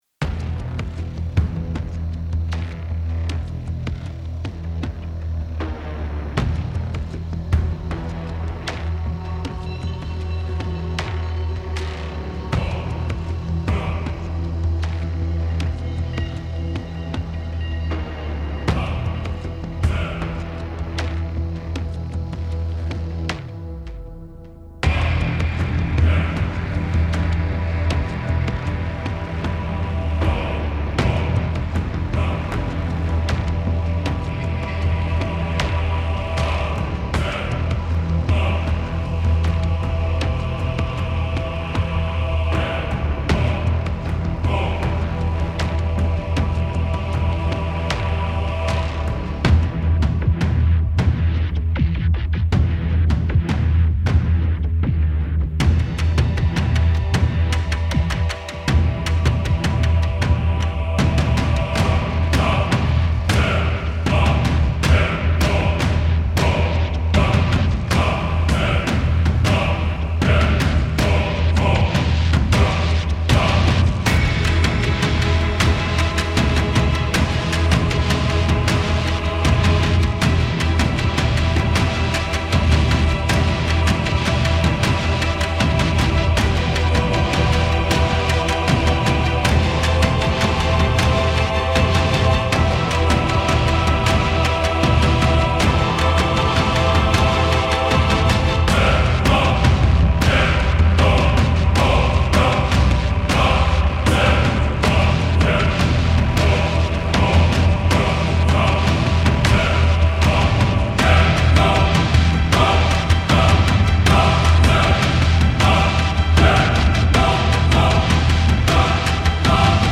soundtrack